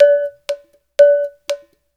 120 -UDU 0GR.wav